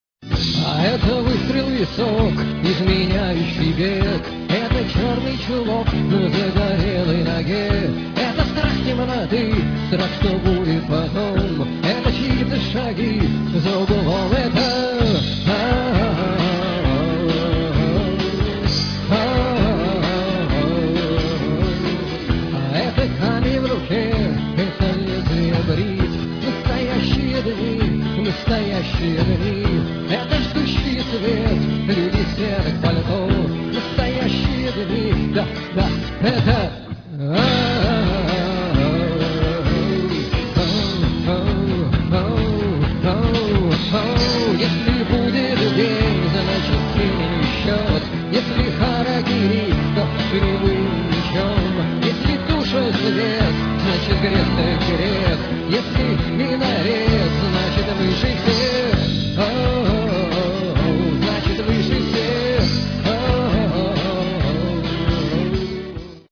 Шаболовка (1993)
фрагмент песни ( 1 мин 8 сек)